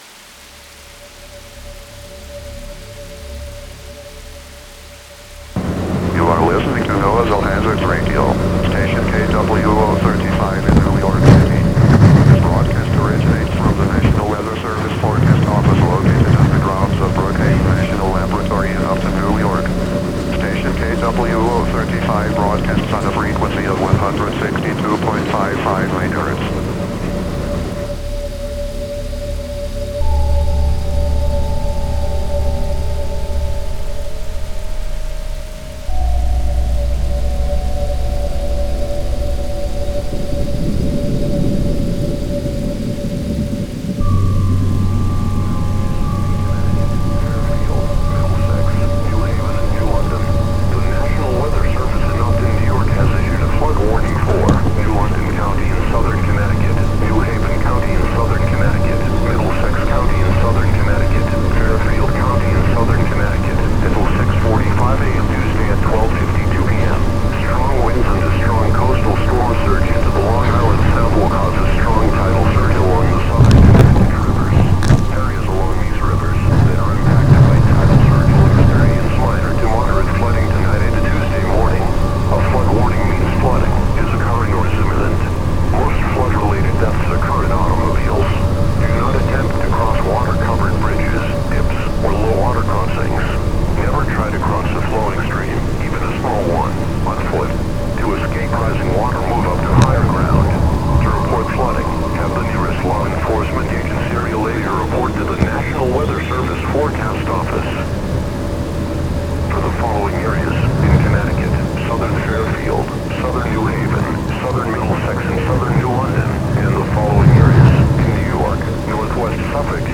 electroacoustic music project